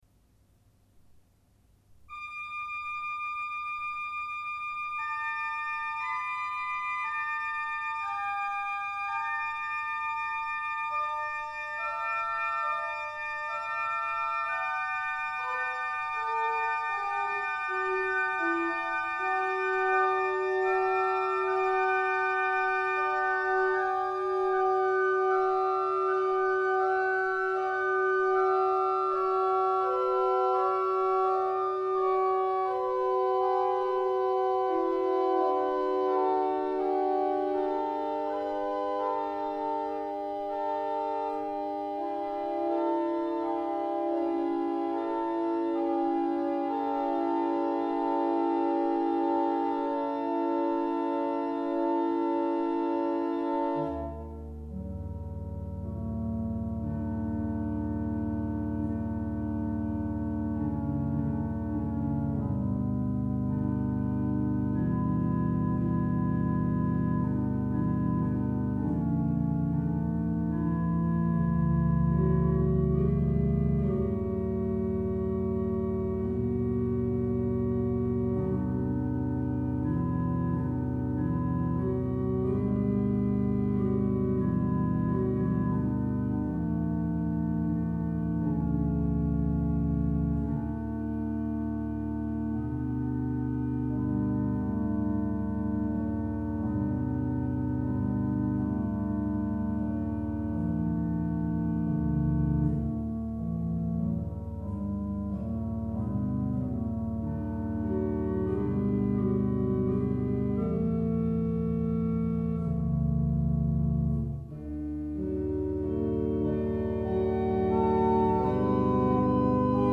Improv-19-groennaasen.mp3